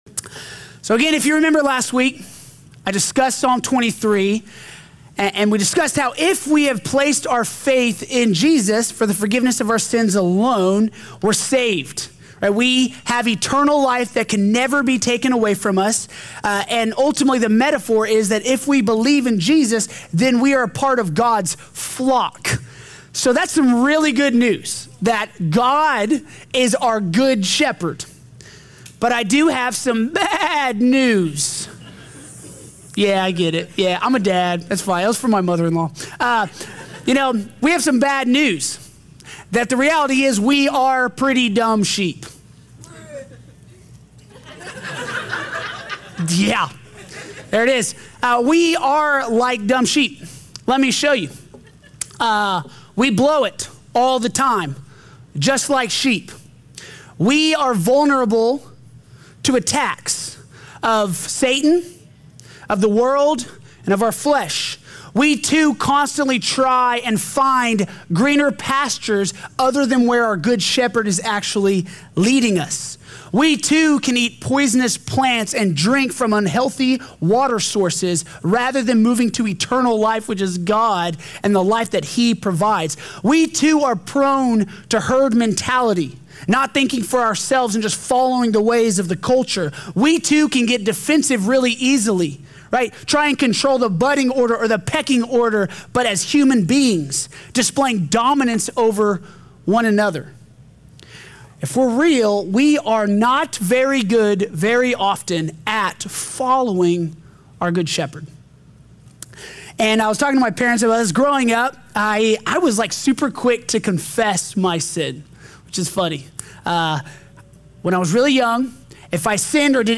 The Prayer of a Penitent Person | Sermon | Grace Bible Church